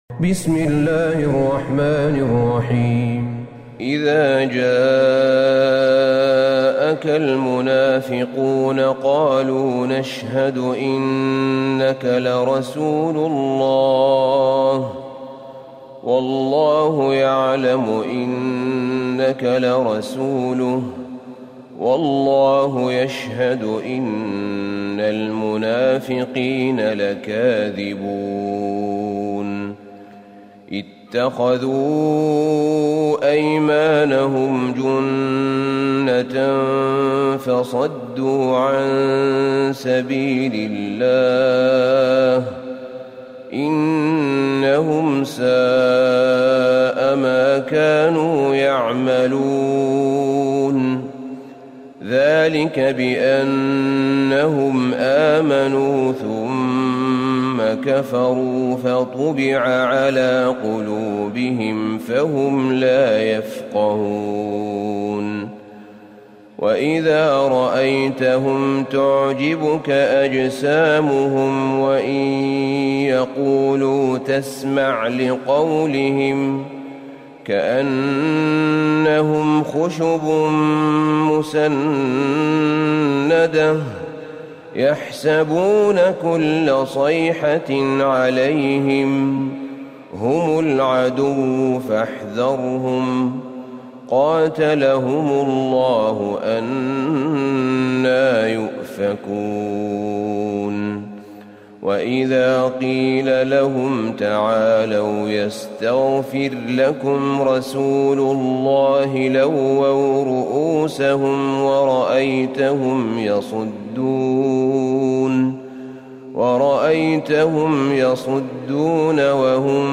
سورة المنافقون Surat Al-Munafiqun > مصحف الشيخ أحمد بن طالب بن حميد من الحرم النبوي > المصحف - تلاوات الحرمين